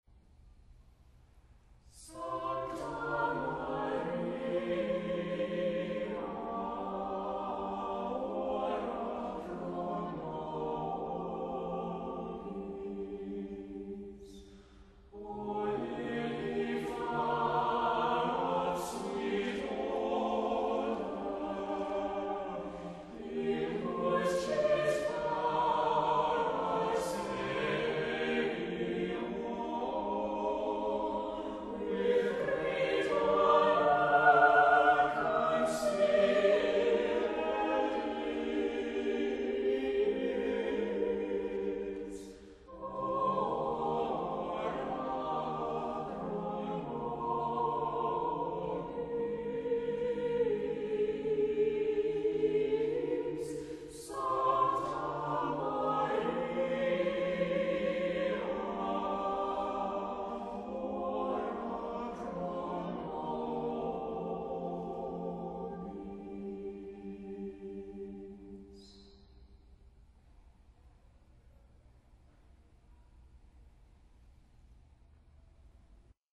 * both are short, in verse/refrain style
* solo can be sung by either soprano or tenor